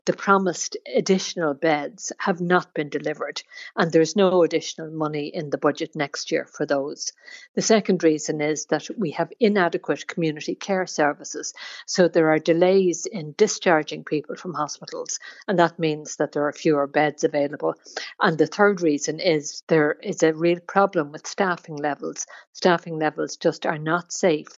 Social Democrats Health Spokesperson, Roisin Shortall , believes there are three reasons as to why overcrowding is such an issue: